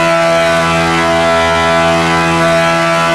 rr3-assets/files/.depot/audio/Vehicles/v8_f1/f1_v8_high.wav
f1_v8_high.wav